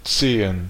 Ääntäminen
Ääntäminen Tuntematon aksentti: IPA: /ˈtseːən/ Haettu sana löytyi näillä lähdekielillä: saksa Käännöksiä ei löytynyt valitulle kohdekielelle.